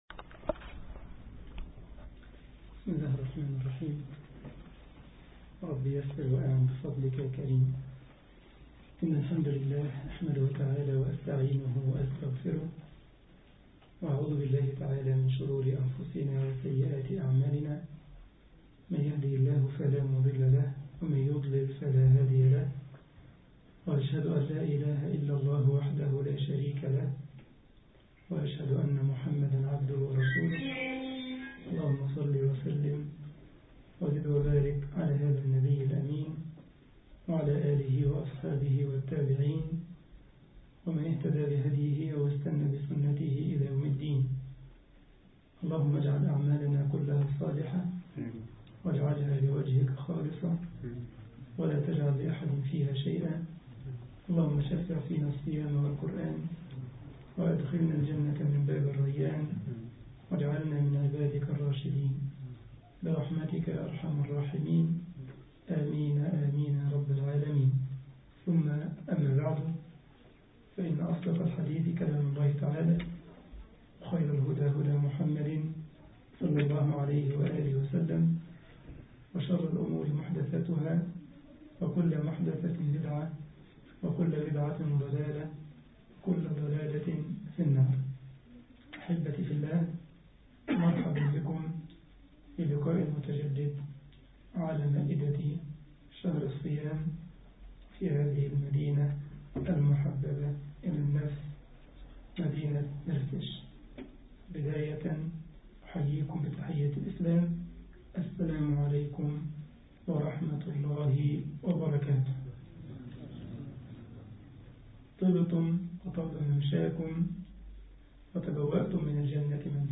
مسجد الجمعية الإسلامية بمرتسش ـ ألمانيا درس 10 رمضان 1433 هـ